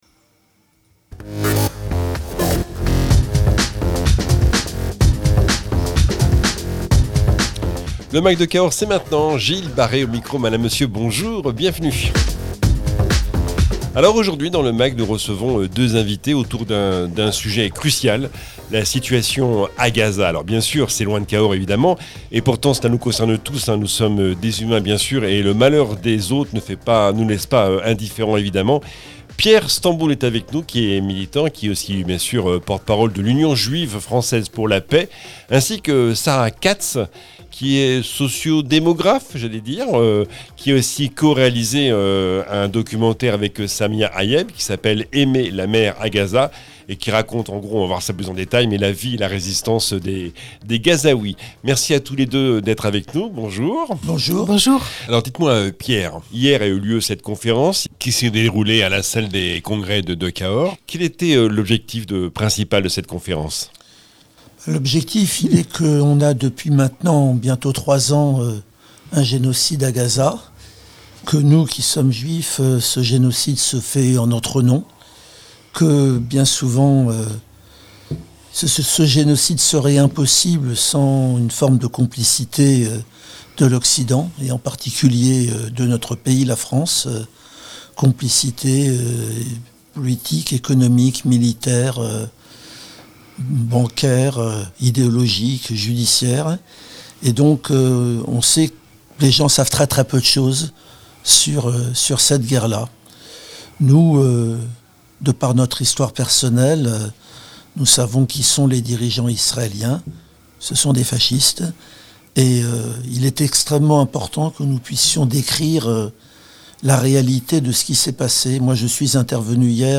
Conférence-débat : la situation à Gaza